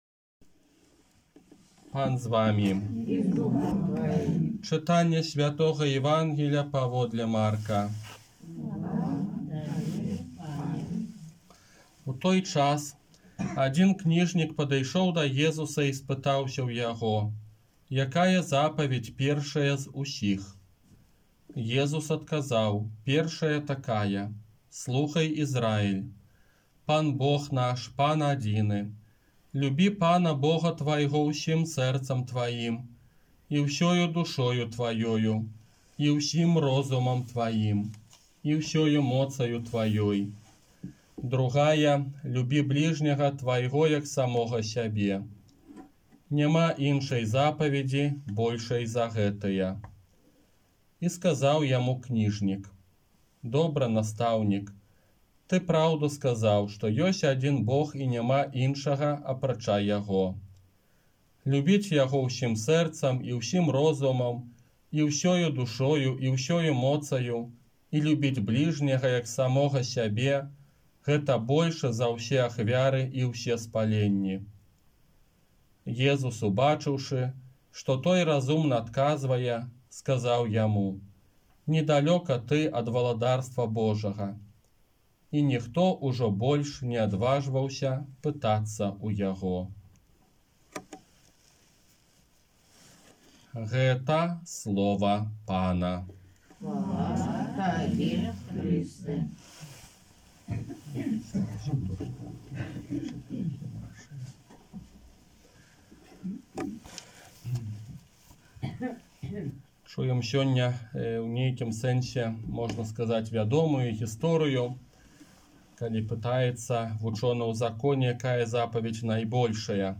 ОРША - ПАРАФІЯ СВЯТОГА ЯЗЭПА
Казанне на трыццаць першую звычайную нядзелю 31 кастрычніка 2021 года